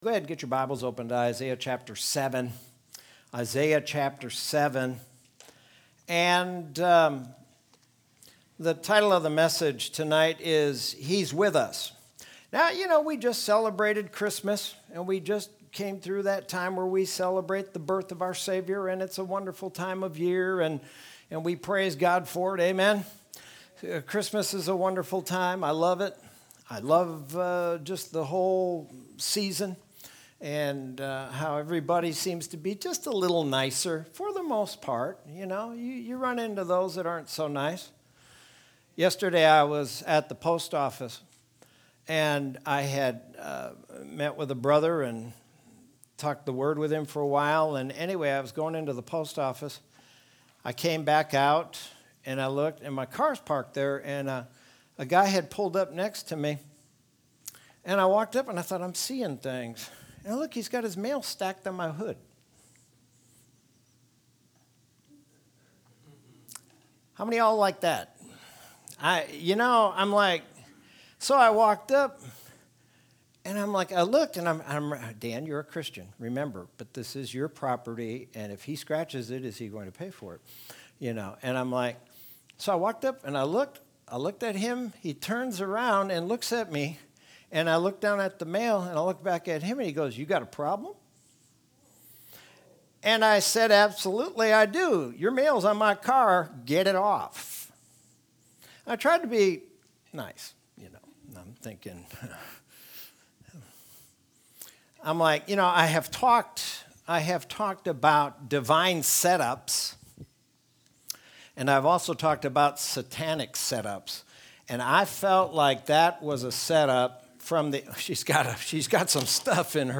Sermon from Wednesday, December 30th, 2020.